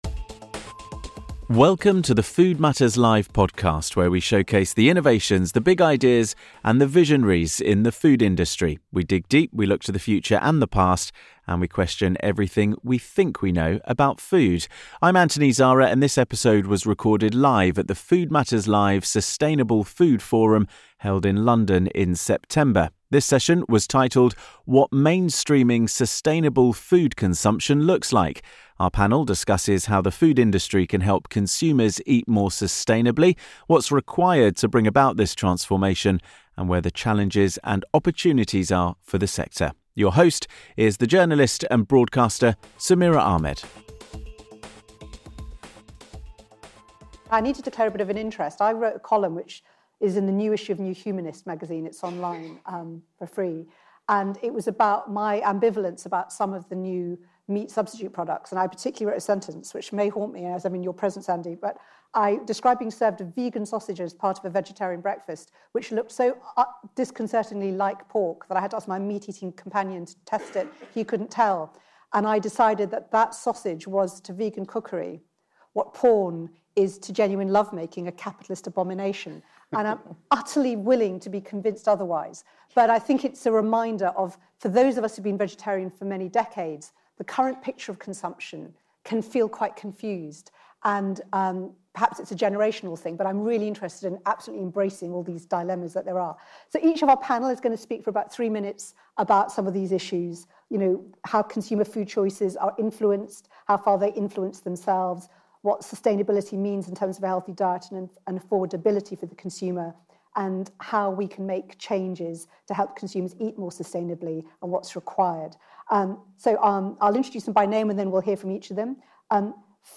In this episode of the Food Matters Live podcast, recorded live at our Sustainable Food Forum event in London, we delve into those questions. Consumer food choices are influenced by what is available, affordable and accessible. So, how can the food industry collaborate to help consumers eat more sustainably?